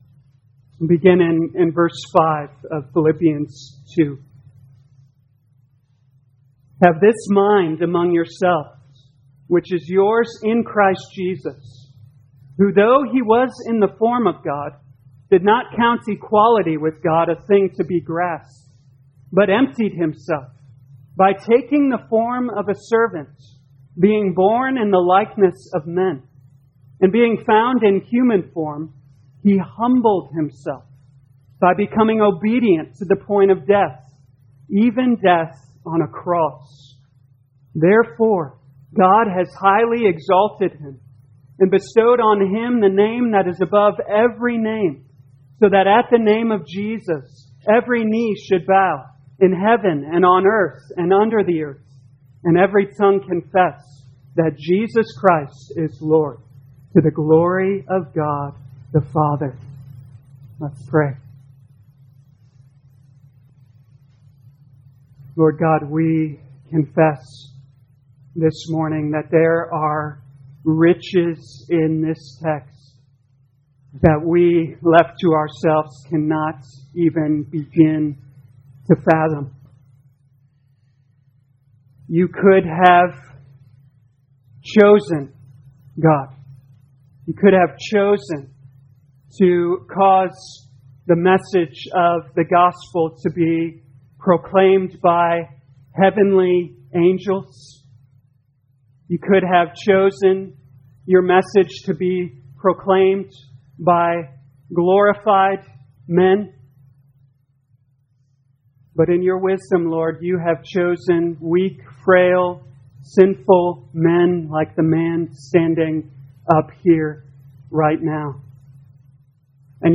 2021 Philippians Humility Morning Service Download